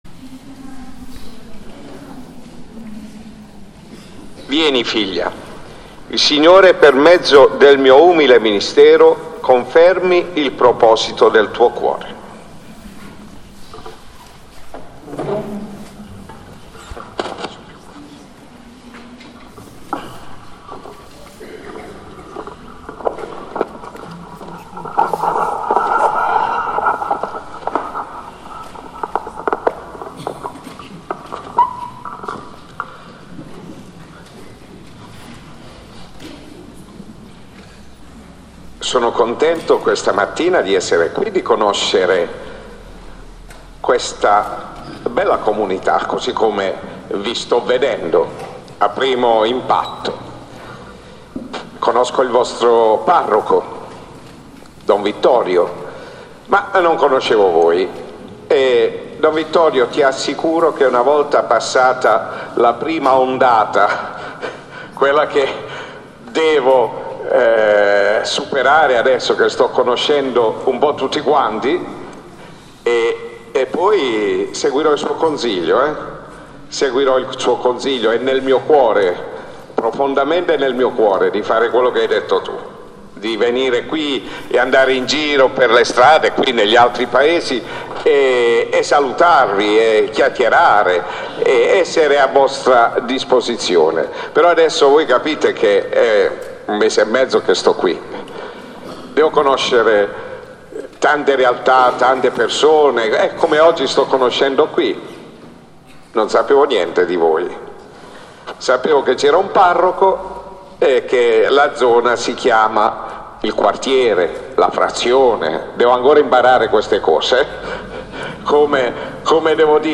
Professione dei Primi Voti e Vestizione Religiosa